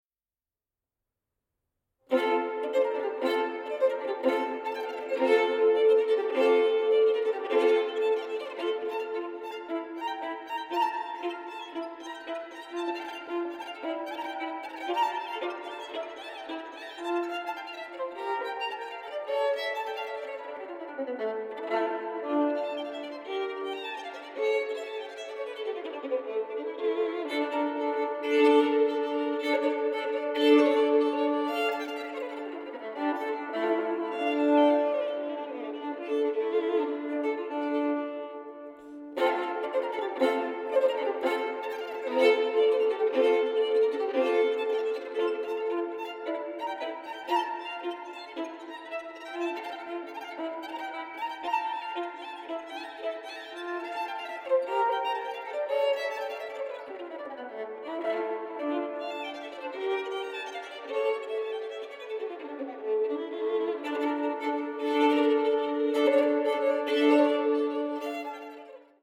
• Genres: Baroque, Classical, Strings